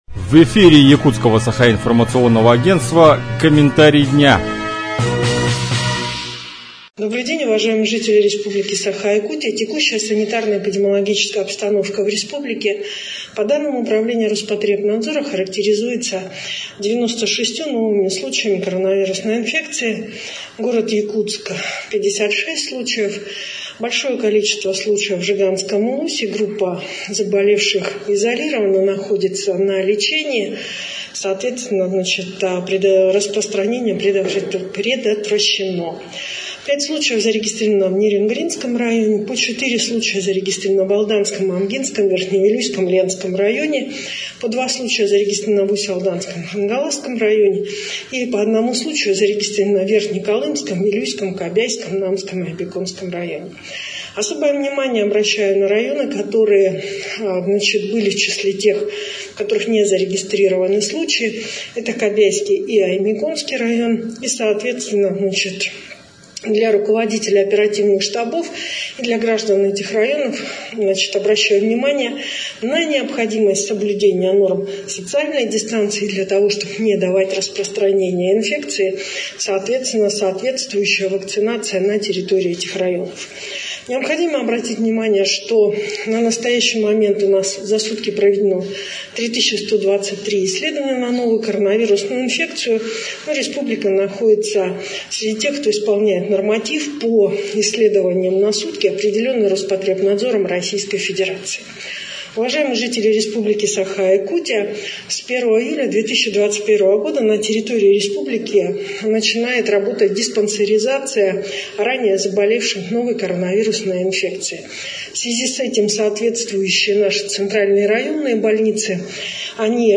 Информацию об эпидемиологической ситуации по состоянию на 2 июля в регионе озвучила вице-премьер Якутии Ольга Балабкина.